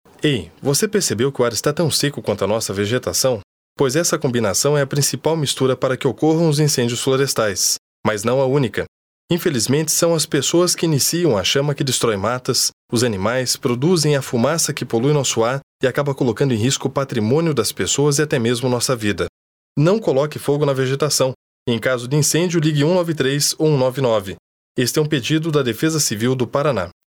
Várias rádios receberam o spot e estão difundindo as informações da campanha durante sua programação.